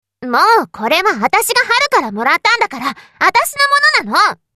サンプルボイス：　【１】